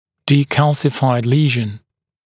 [diː’kælsɪfaɪd ‘liːʒn][ди:’кэлсифайд ‘ли:жн]место деминерализации эмали